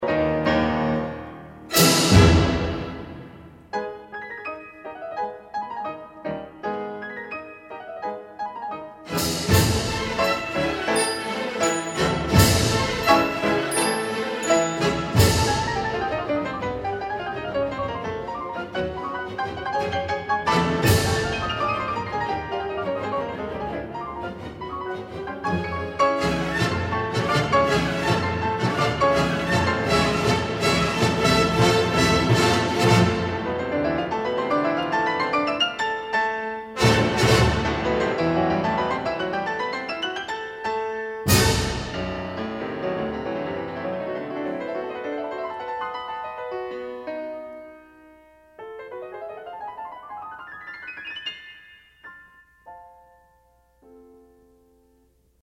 活泼的快板